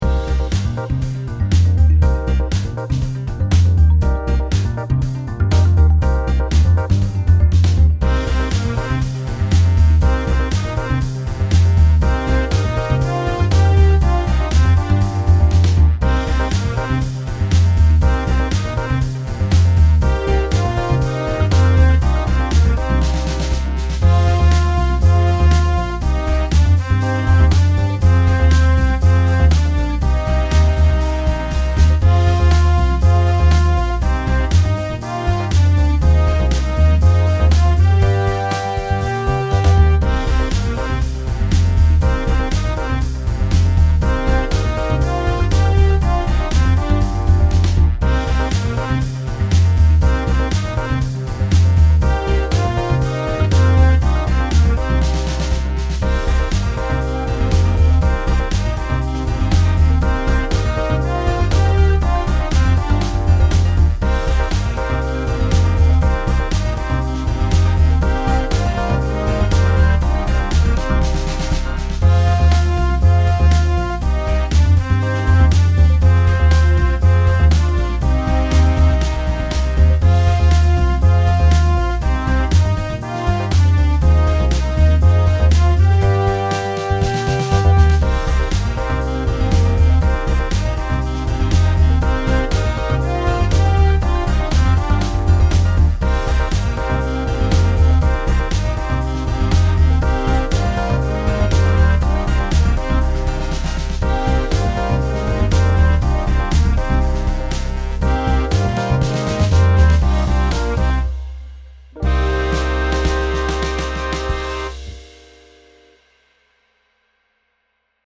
Key: C Minor Tempo: Rock! 120bpm